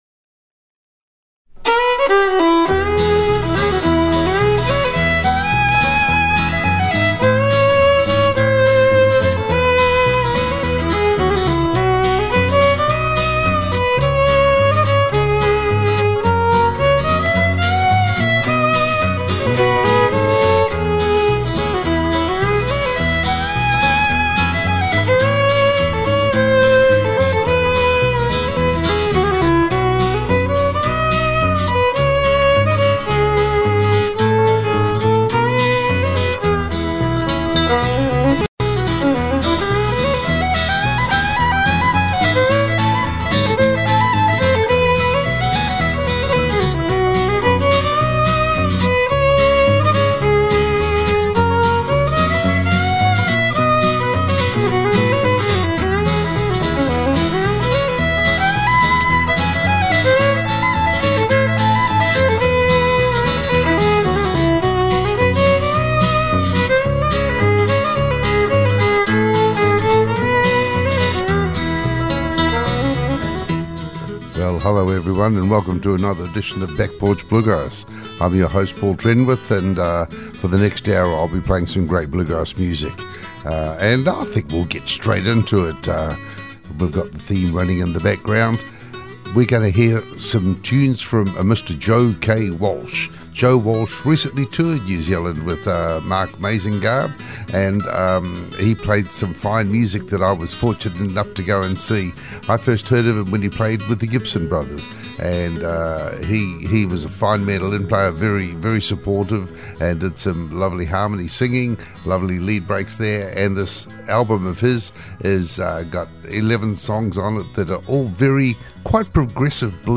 Back Porch Bluegrass Show - 19 June 2018